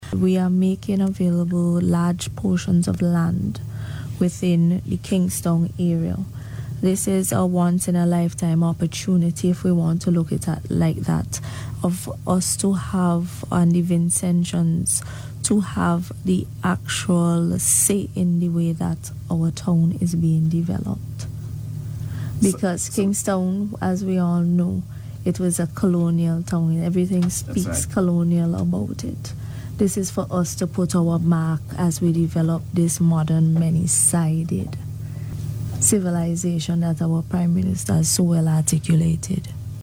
This is according to Minister of Urban Development, Benarva Browne, as she outlined plans for the future development of the area on Radio recently.